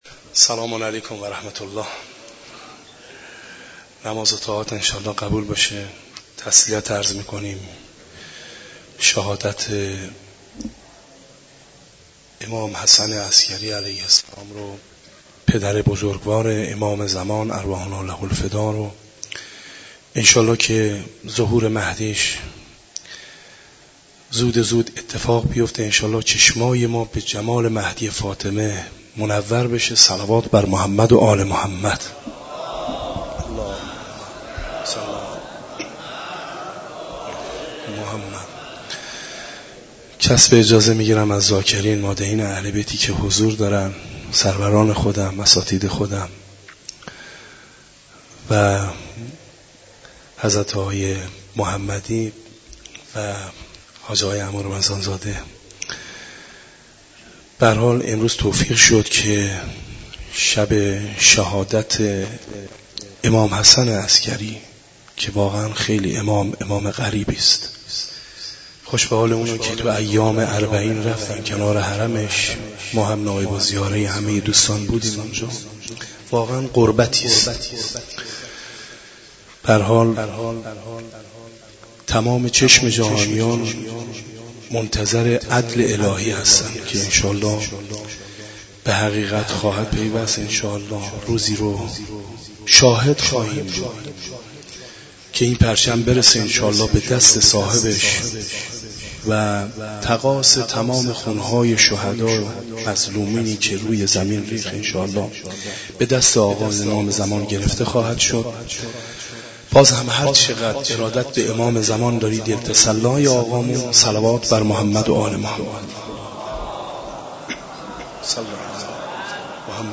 برگزاری مراسم عزاداری به مناسبت شهادت امام حسن عسگری علیه السلام در مسجد دانشگاه کاشان
مراسم عزاداری به مناسبت شهادت امام حسن عسگری علیه السلام در مسجد دانشگاه کاشان
مداحی